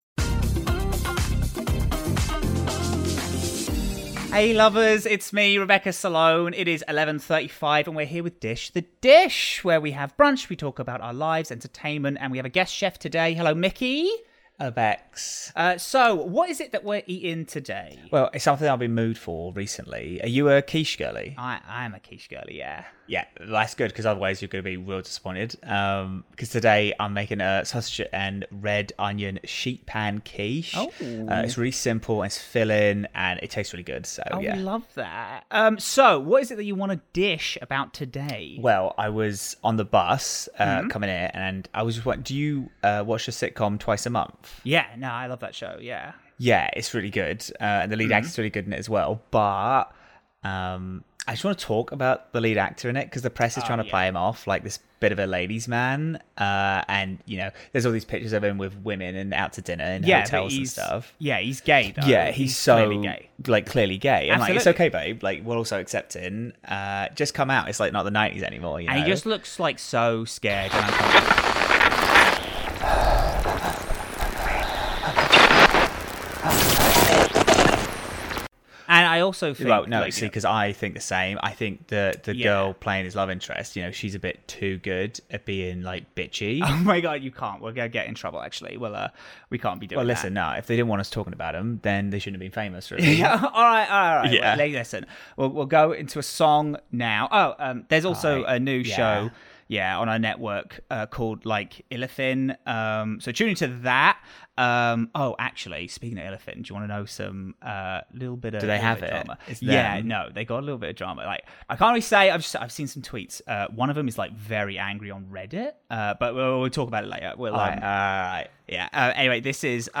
Library of Audio Fiction Podcasts
TW: Frequencies contains loud sound effects including sudden banging and audio jump-scares, and this episode surrounds topics of anxiety, medication, mental health struggles, alcohol, and upsetting social tension.